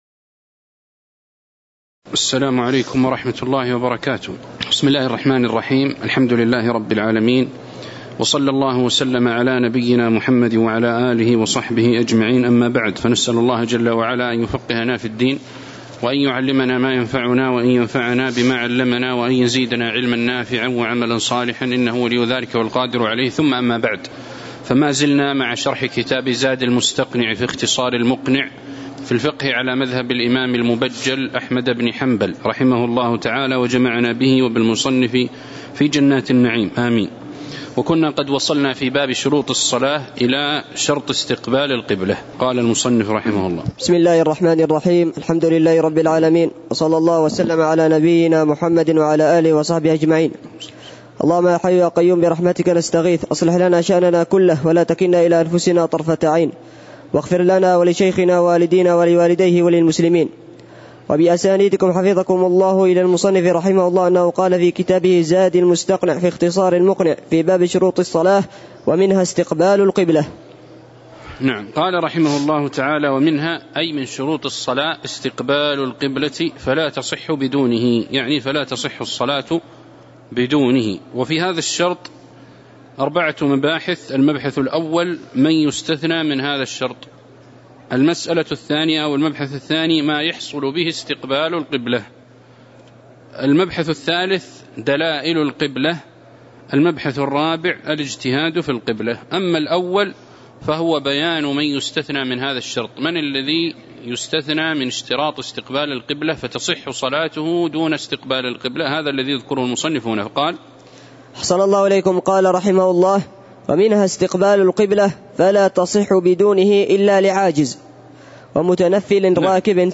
تاريخ النشر ٢٨ محرم ١٤٤٠ هـ المكان: المسجد النبوي الشيخ